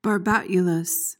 PRONUNCIATION:
(bar-BAT-yuh-luhs)